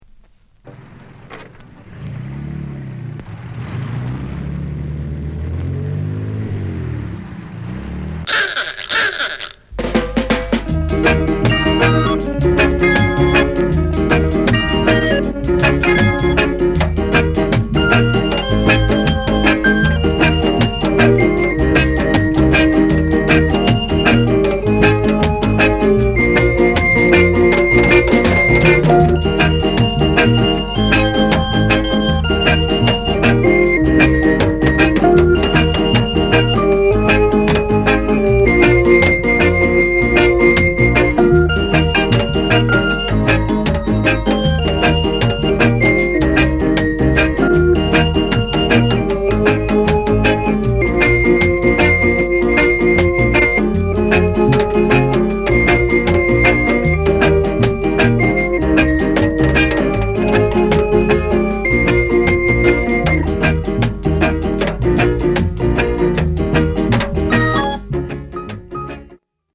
RARE SKINHEAD